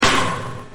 Download Half Life Clang sound effect for free.
Half Life Clang